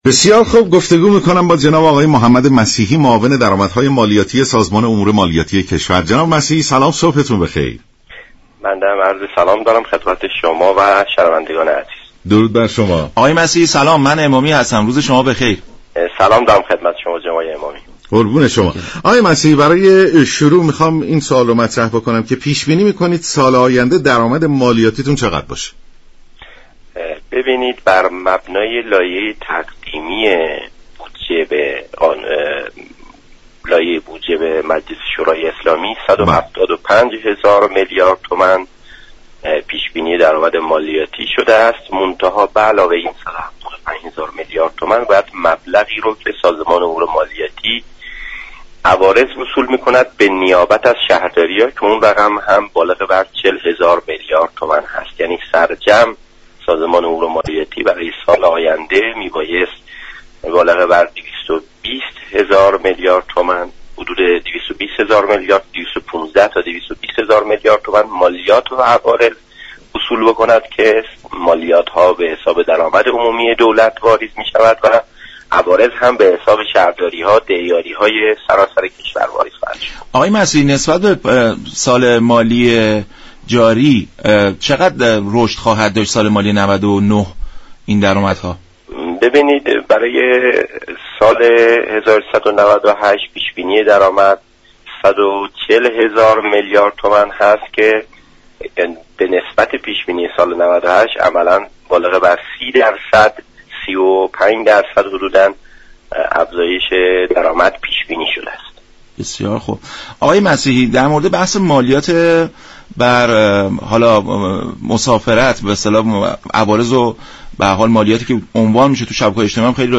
به گزارش شبكه رادیویی ایران، «محمد مسیحی» معاون درآمدهای مالیاتی سازمان امور مالیاتی كشور در برنامه «سلام صبح بخیر» درباره درآمدهای مالیاتی سال 99 گفت: طبق لایحه تقدیمی بودجه سال 99 به مجلس شورای اسلامی، سازمان امور مالیاتی كشور، در آمد مالیاتی سال آینده را 220 هزار میلیارد تومان پیش بینی كرده است.